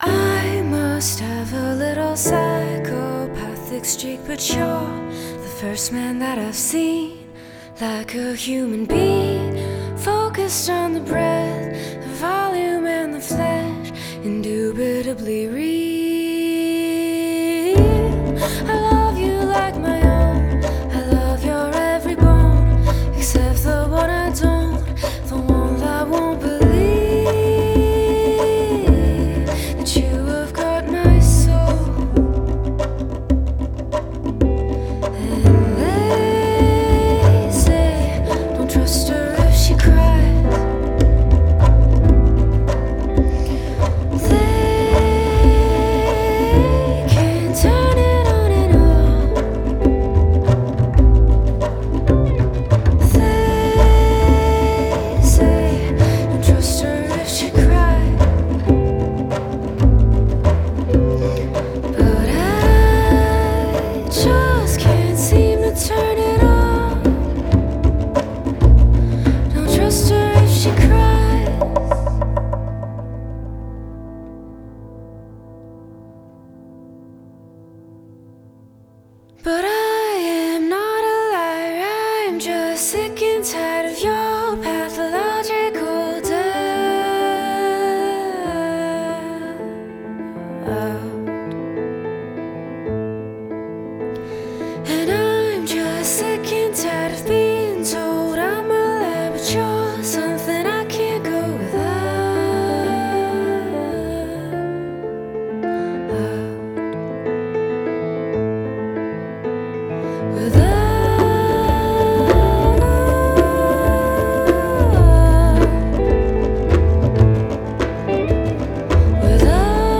piano, chant
contrebasse